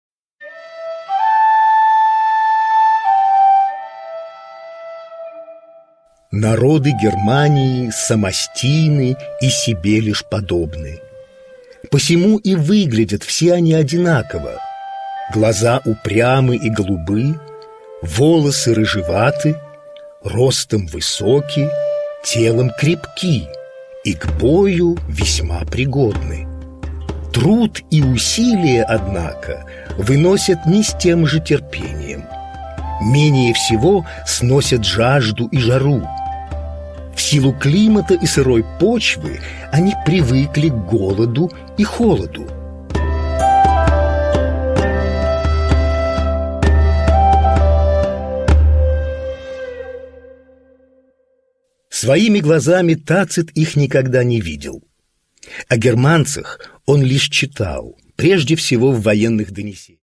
Produkttyp: Hörbuch-Download
Fassung: inszenierte Lesung mit Musik